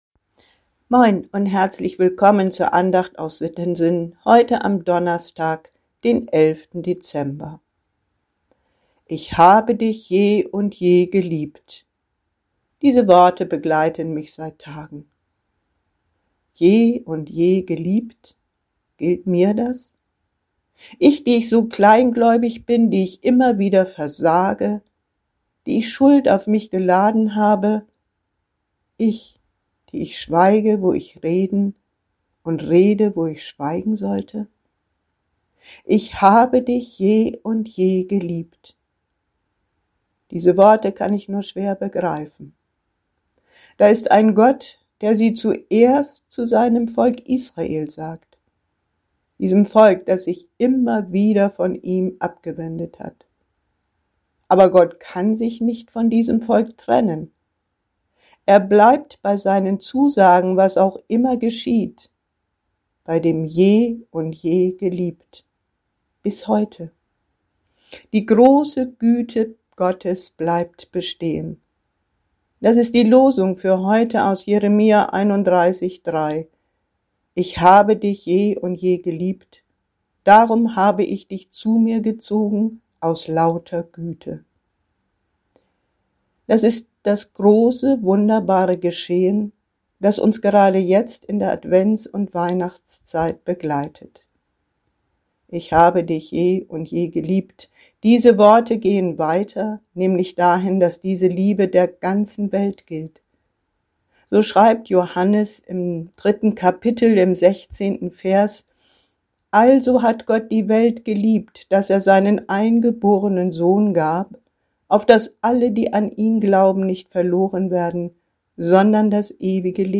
Audio-Dateien der Andachten (Herrnhuter Losungen)